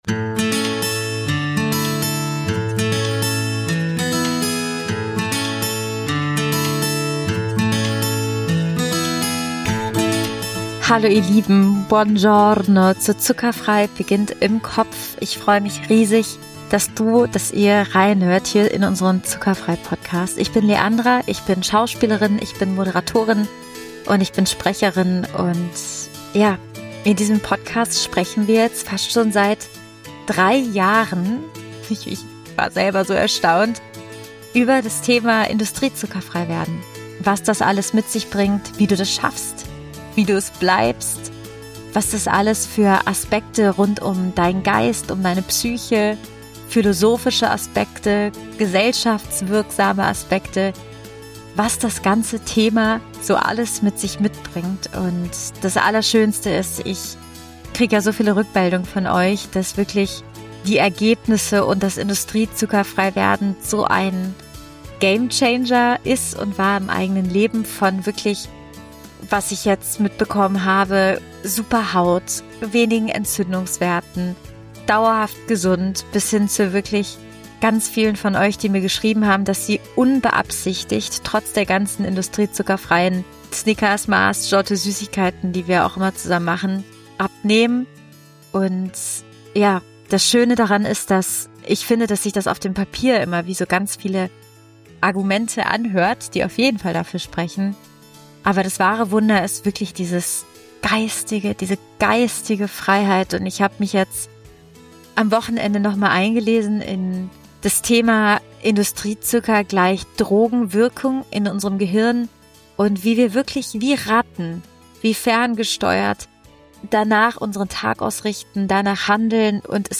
Intuitiv essen Interview